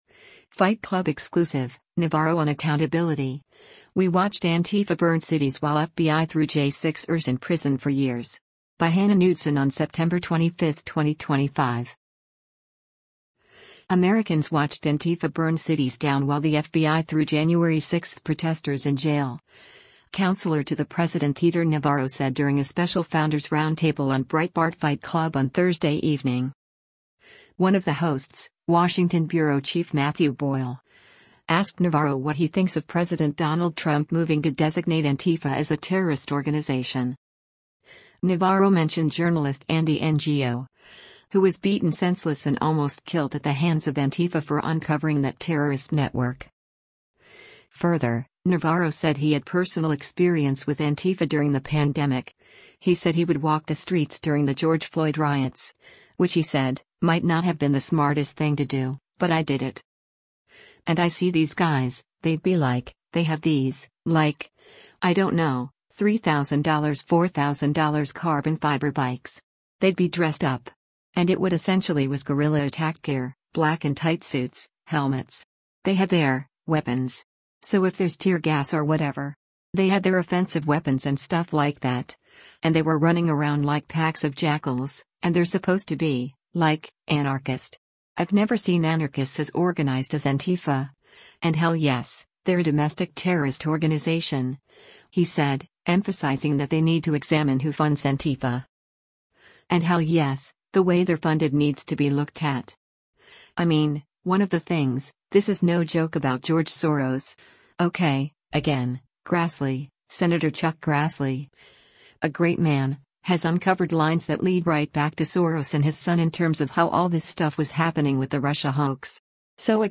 Americans watched Antifa burn cities down while the FBI threw January 6 protesters in jail, Counselor to the President Peter Navarro said during a special Founders Roundtable on Breitbart Fight Club on Thursday evening.